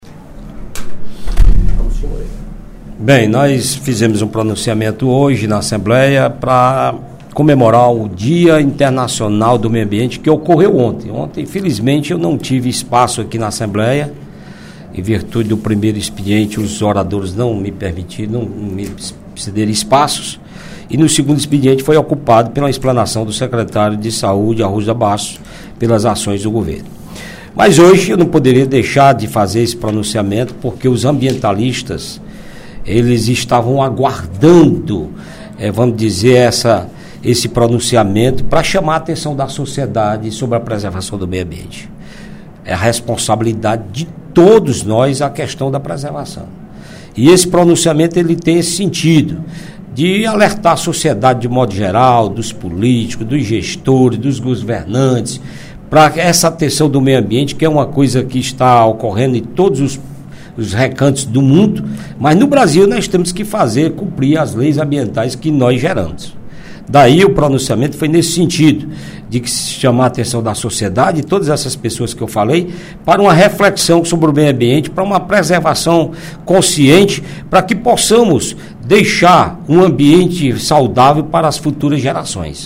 Durante o primeiro expediente da sessão plenária desta quinta-feira (06/06), o deputado Augustinho Moreira (PV) destacou o Dia Mundial do Meio Ambiente, comemorado ontem.